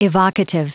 Prononciation du mot evocative en anglais (fichier audio)
Prononciation du mot : evocative